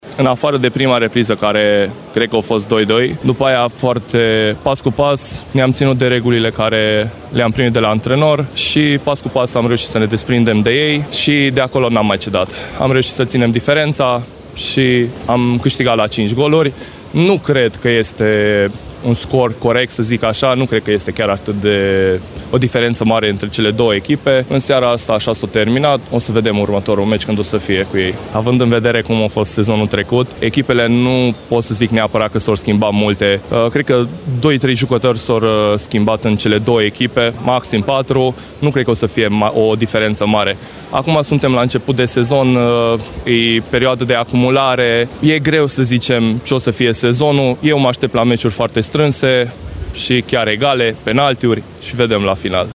Concluzii după acest joc a tras unul dintre jucătorii învingătorilor